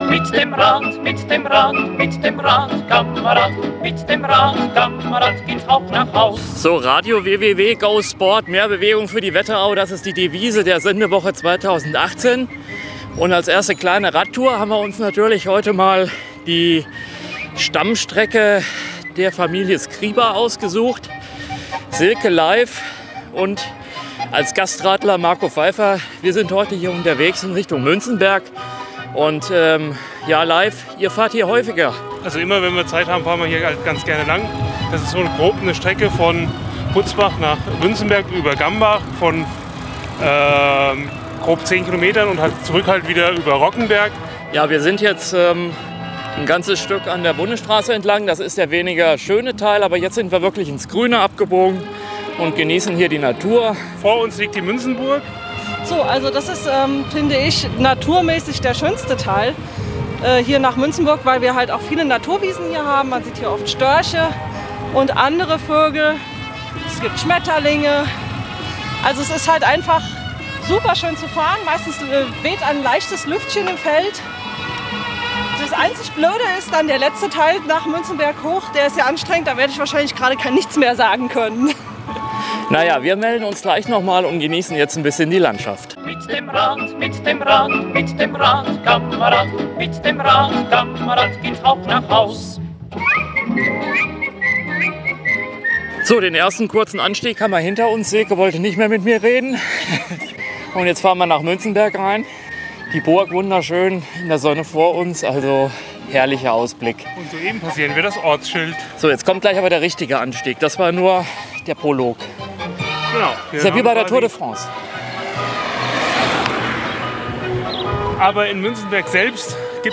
BEITRAG-Tour-de-Wetterau-Münzenberg.mp3